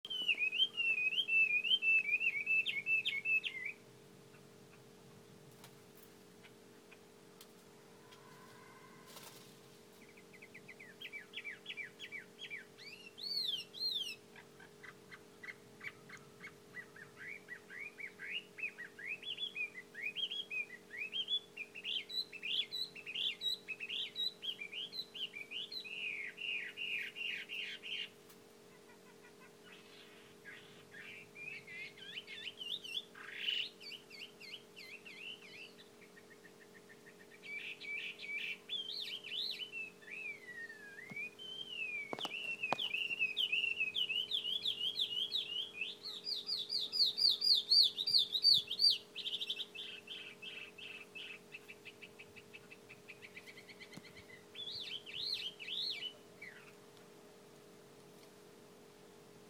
今日のうぐいす。
ぴーちくぱーちく。